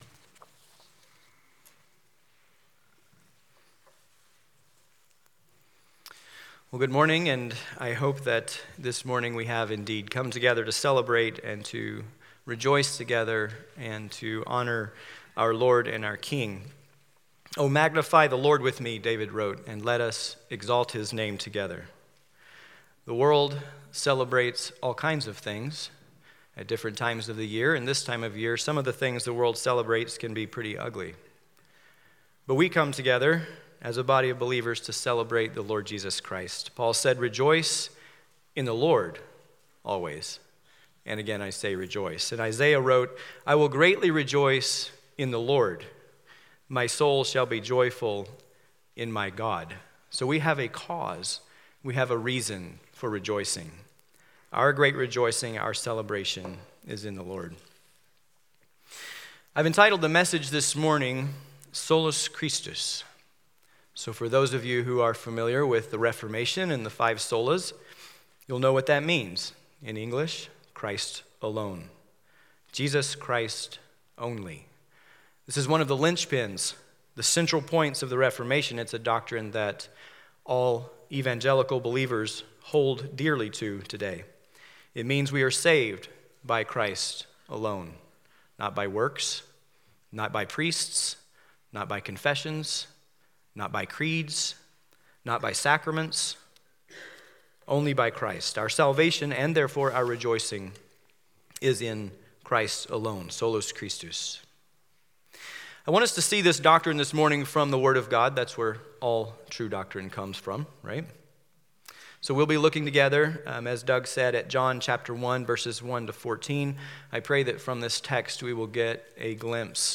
Bible Text: John 1:1-14 | Preacher